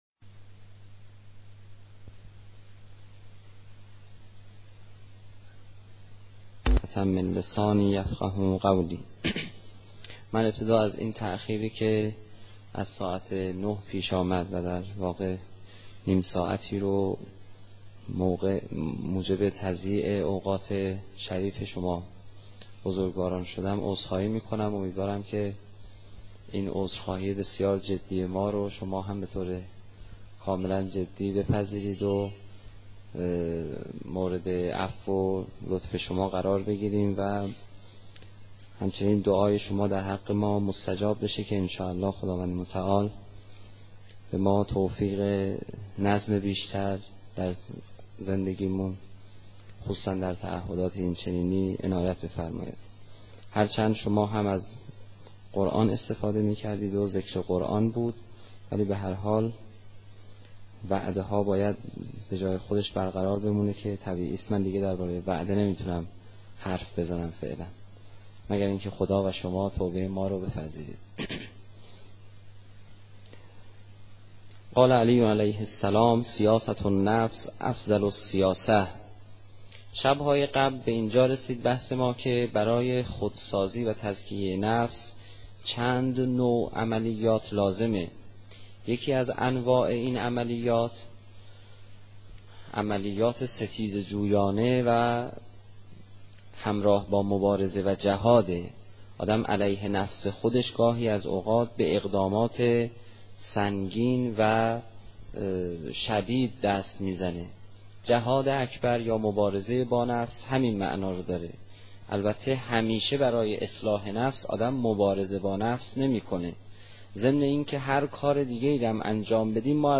سخنرانی حاج آقای پناهیان با موضوع انسان و تزکیه